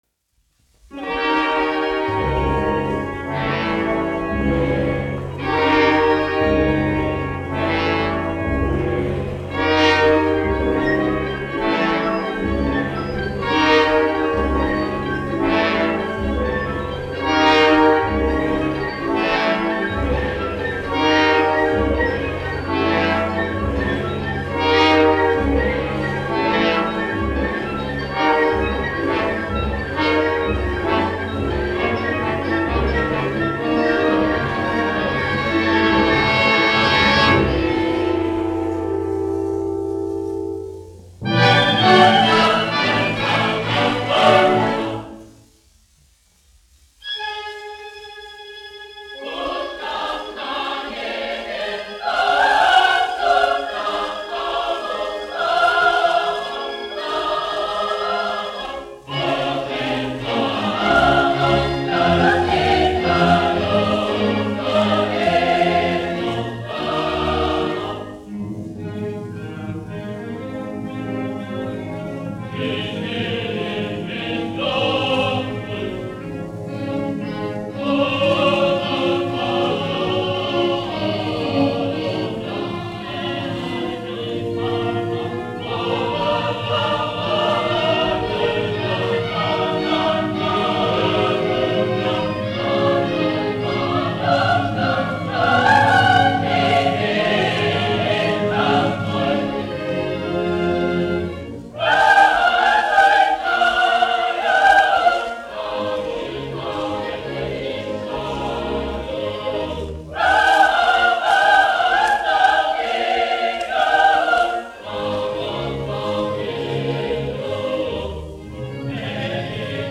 Latvijas Nacionālā opera. Koris, izpildītājs
Emil Cooper, diriģents
1 skpl. : analogs, 78 apgr/min, mono ; 30 cm
Operas--Fragmenti
Latvijas vēsturiskie šellaka skaņuplašu ieraksti (Kolekcija)